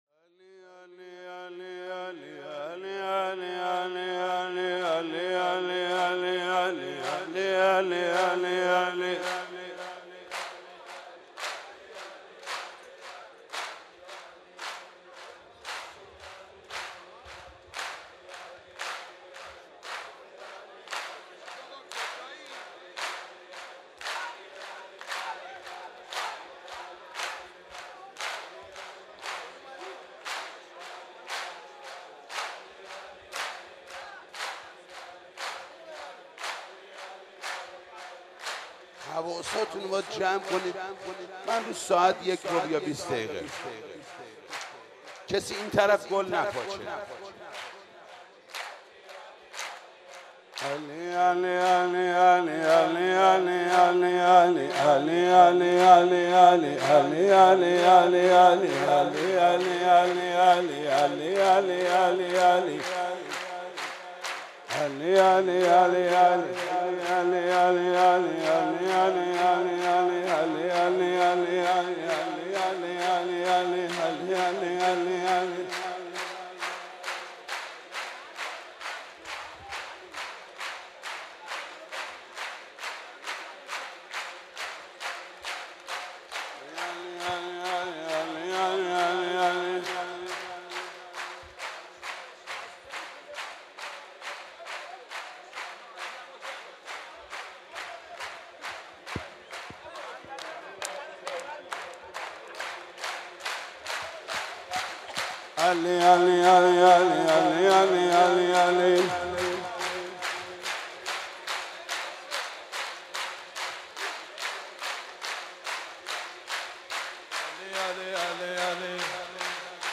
سرود: ای ام لیلا پر در اوردی